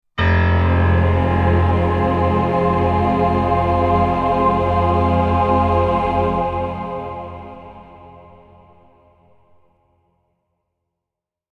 Suspenseful-impact-dramatic-piano-choir-sound-effect.mp3